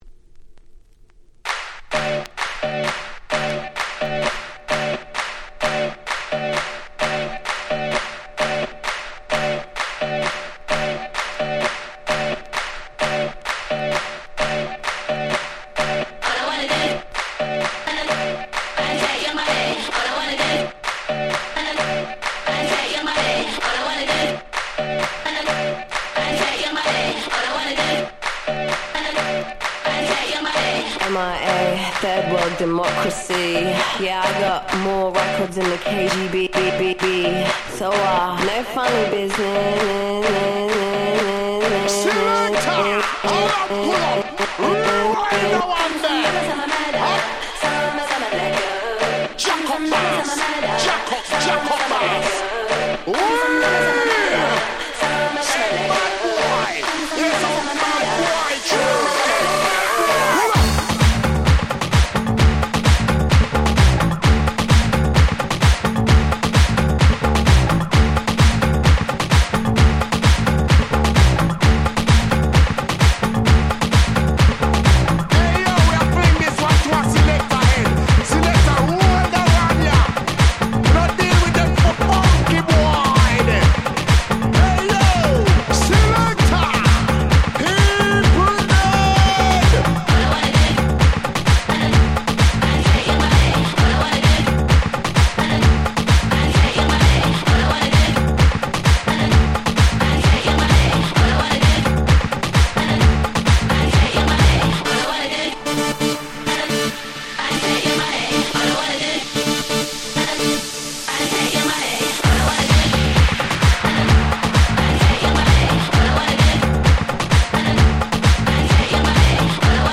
White盤オンリーのアゲアゲParty Tracks/勝手にRemix物！！